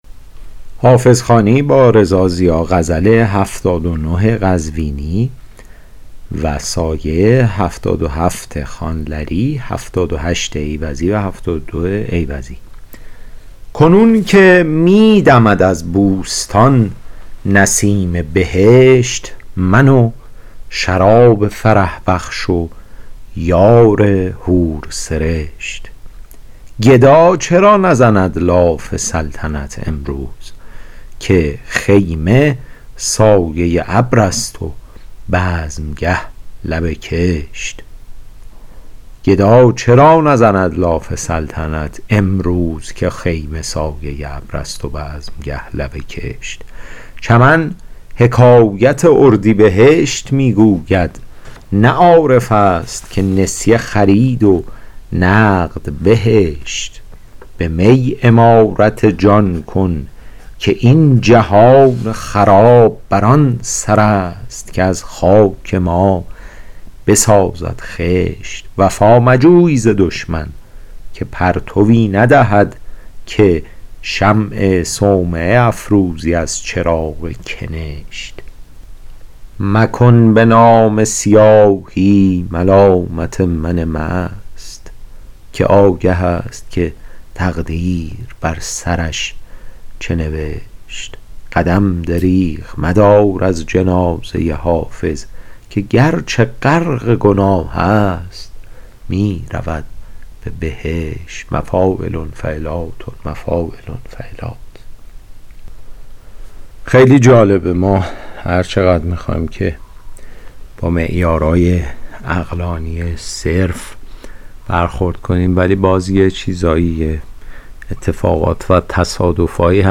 شرح صوتی غزل شمارهٔ ۷۹